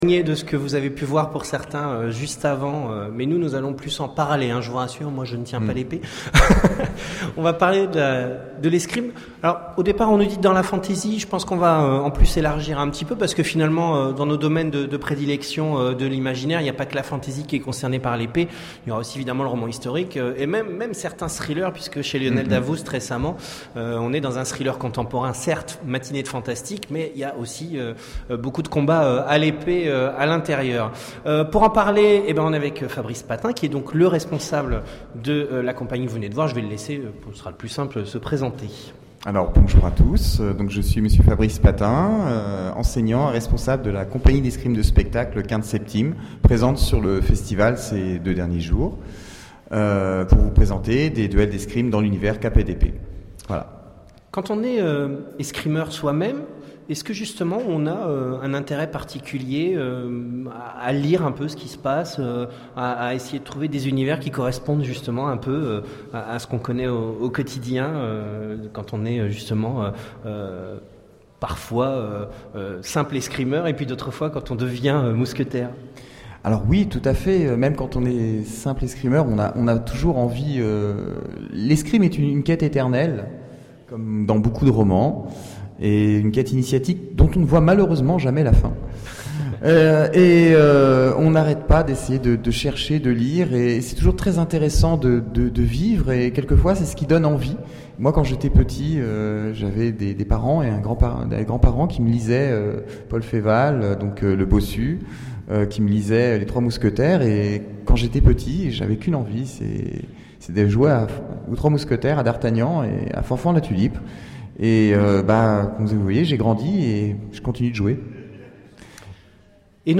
Imaginales 2013 : Conférence La plume est plus forte que l'épée ?